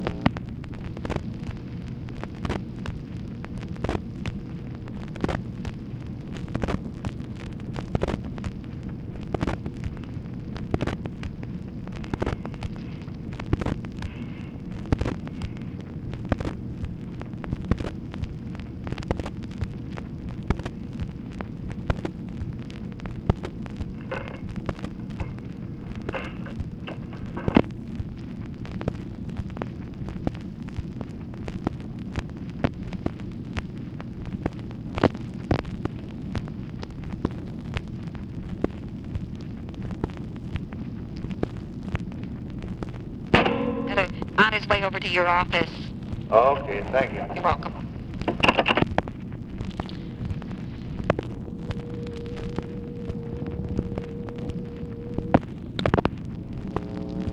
Conversation with TELEPHONE OPERATOR, August 5, 1964
Secret White House Tapes